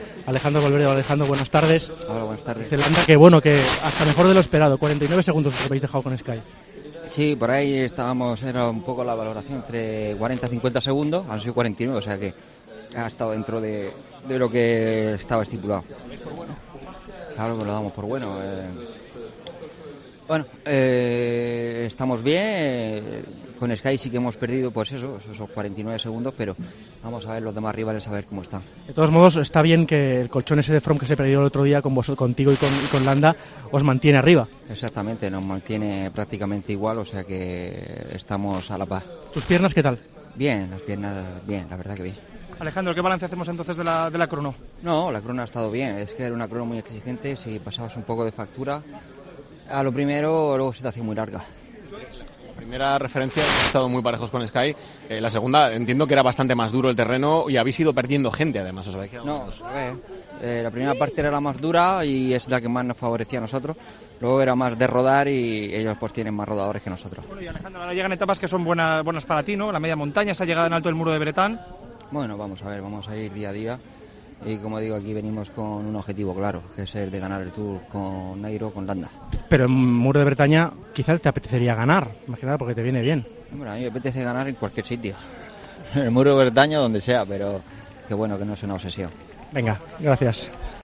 El ciclista de Movistar habló con los medios al final de la tercera estapa: "El colchón que conseguimos en la primera etapa nos mantiene arriba".